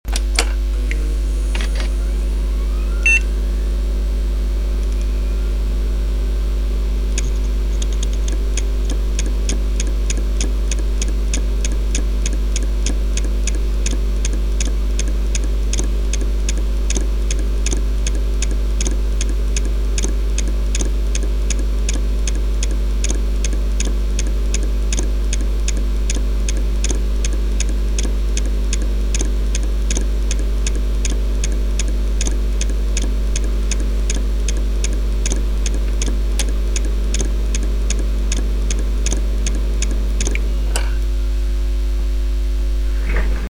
Sounds fehlerhafter HDDs